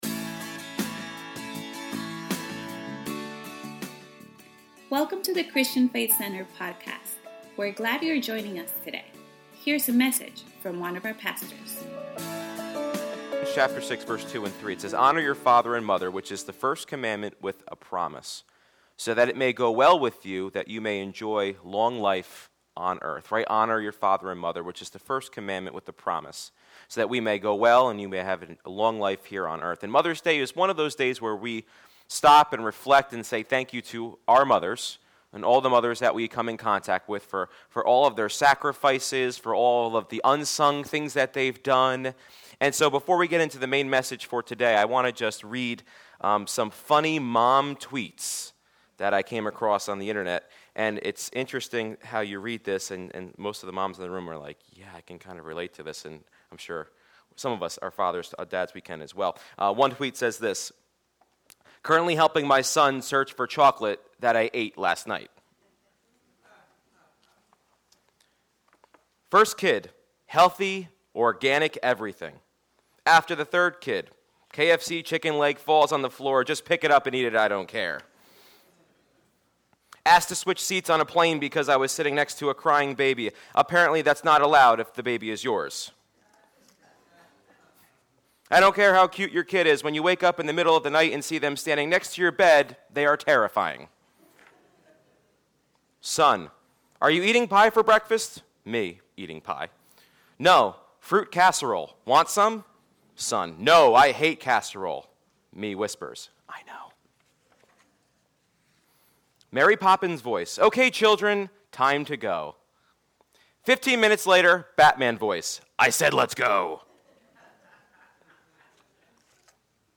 In this Mother’s Day message